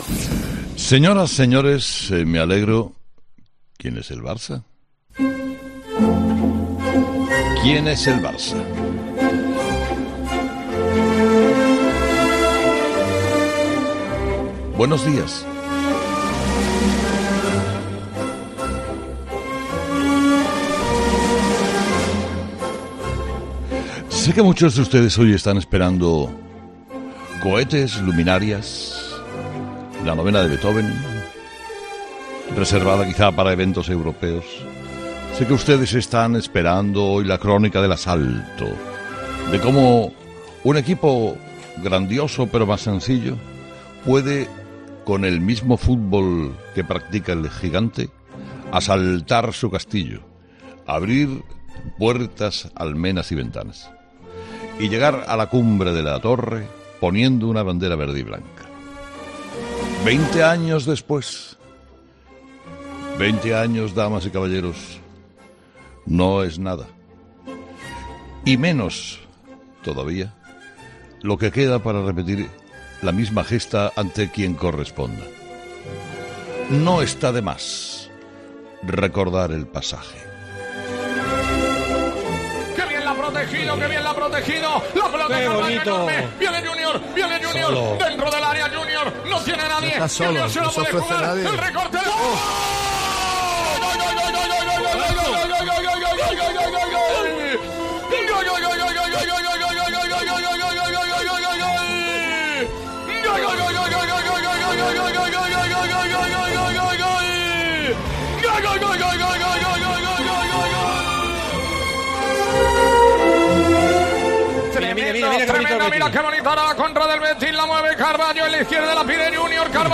El comunicador ha dedicado el inicio de su programa a festejar el triunfo de los de Quique Setién en el Camp Nou
Tras la victoria de este domingo del Betis frente al Barcelona en el Camp Nou, Carlos Herrera no ha querido dejar pasar la ocasión y le ha dedicado el inicio de su monólogo de este lunes a los de Quique Setién.